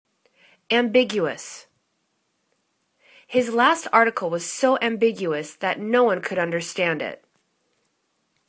am.big.u.ous      /am'bigyuəs/    n